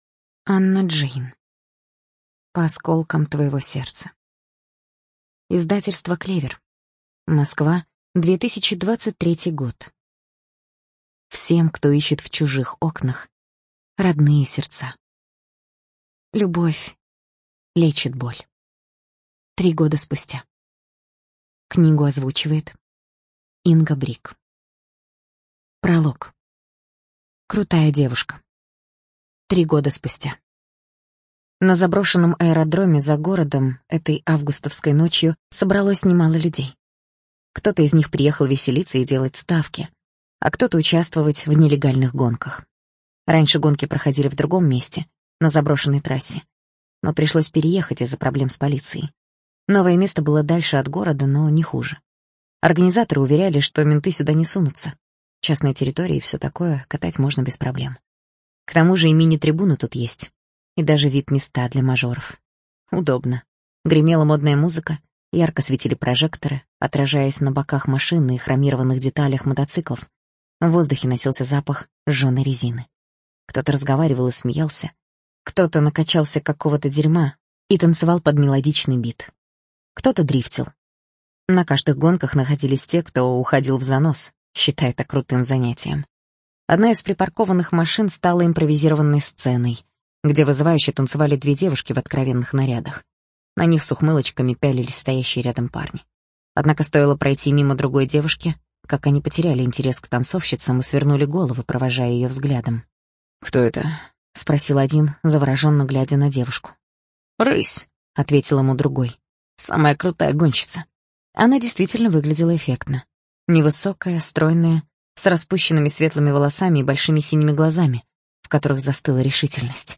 Студия звукозаписиКлевер